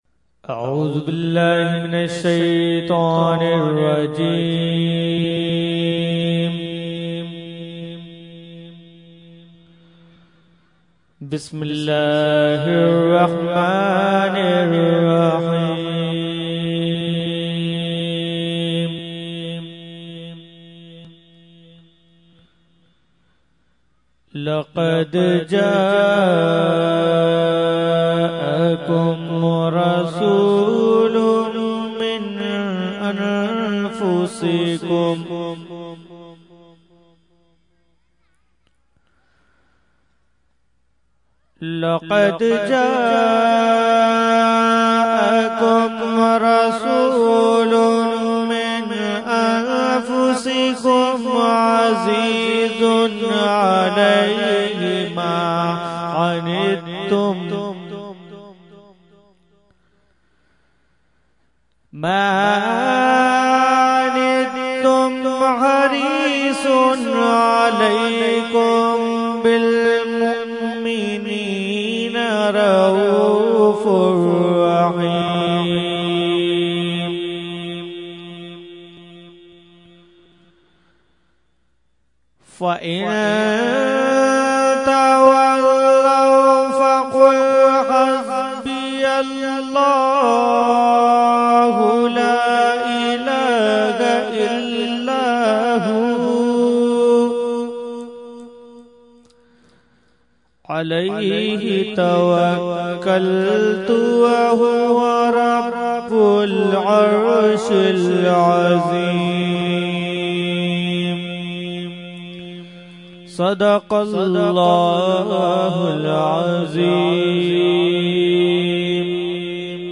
Category : Qirat | Language : ArabicEvent : 11veen Sharif Lali Qila Lawn 2015